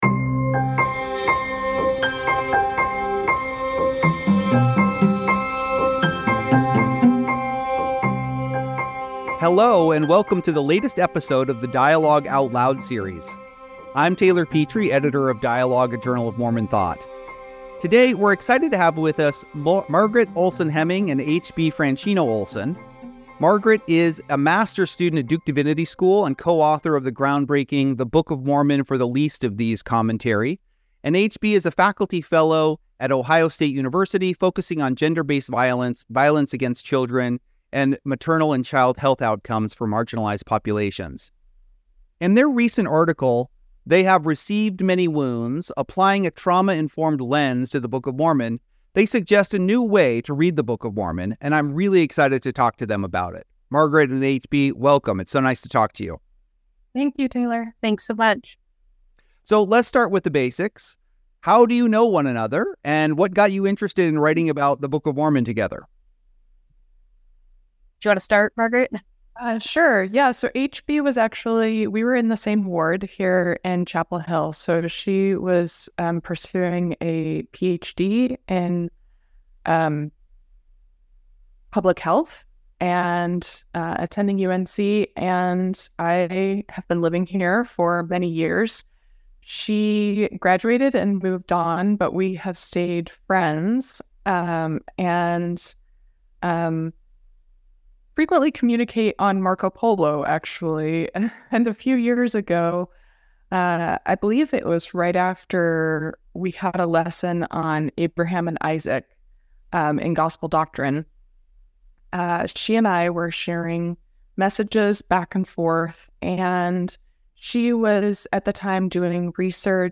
Applying a Trauma-Informed Lens to the Book of Mormon: A Conversation